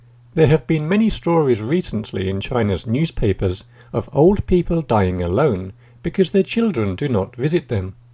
DICTATION 3